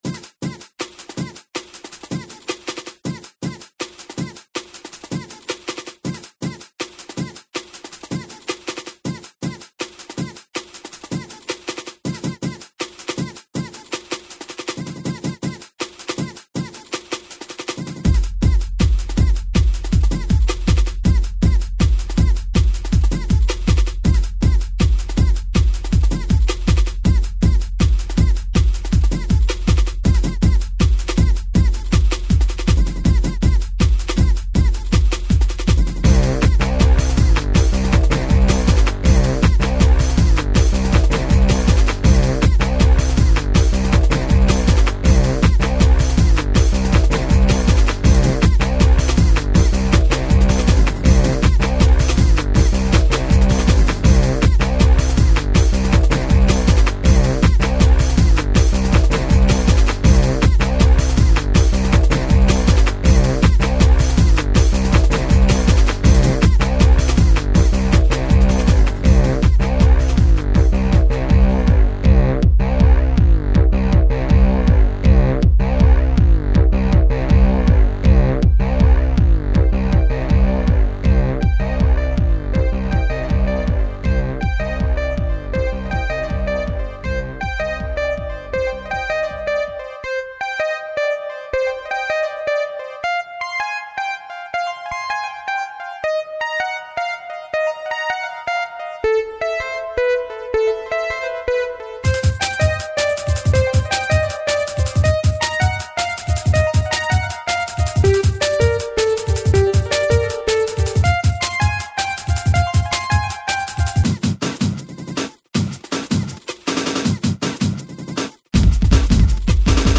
3,3 MB 1998 Hardtrance